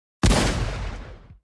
ses-efektleri-silah.mp3